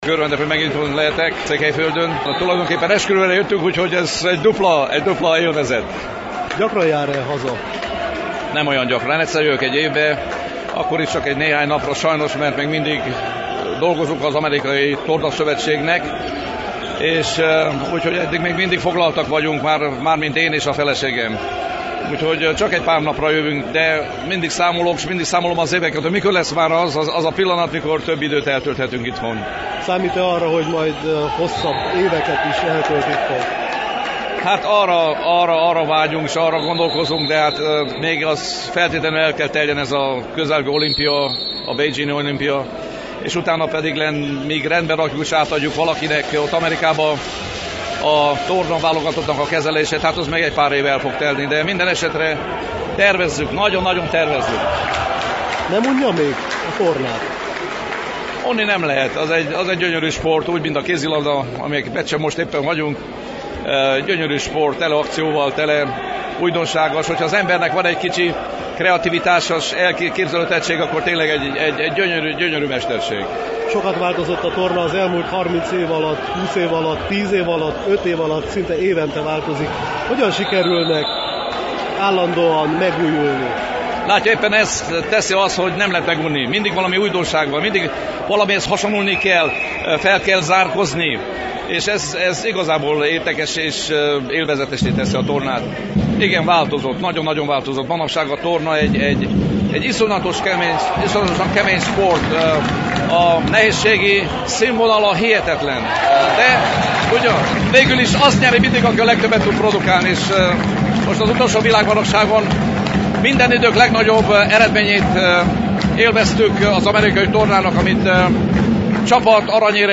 A hangfelvétel 2007-ben készült Székelyudvarhelyen, egy kézilabda mérkőzésen.